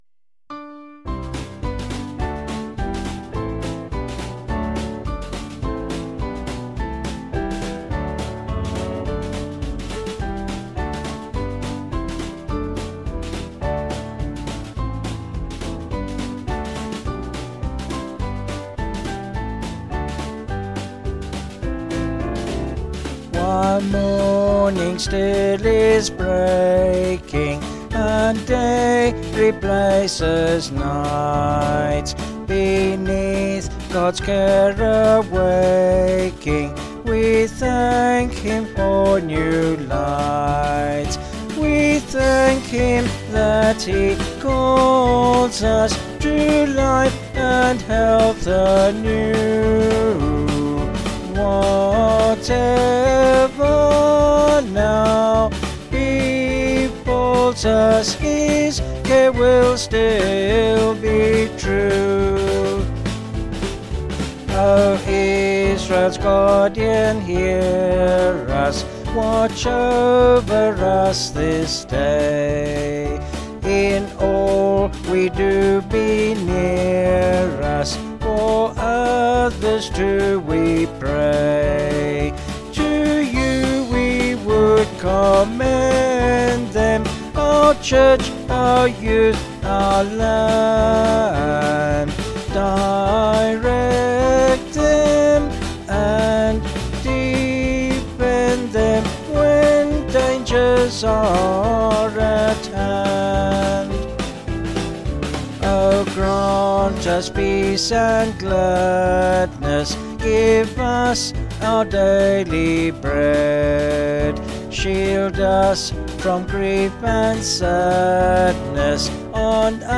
Vocals and Band   703.9kb Sung Lyrics